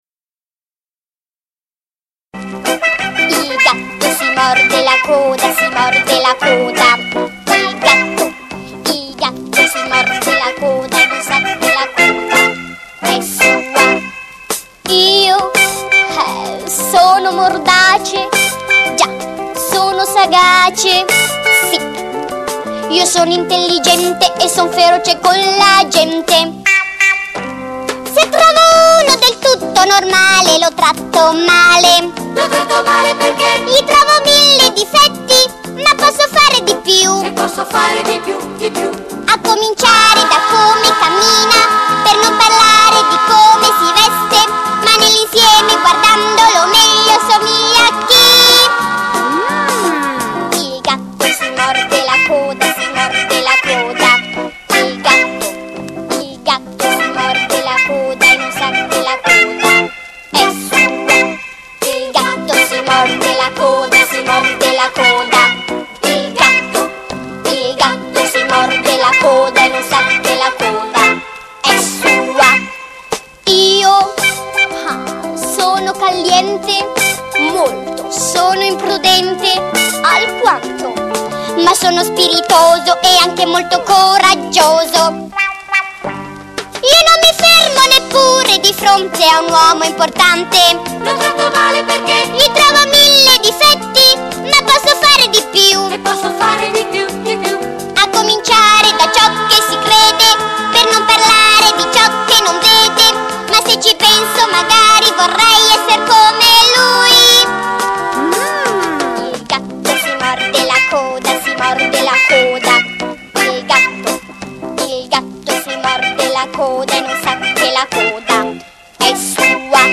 CORO E ORCHESTRA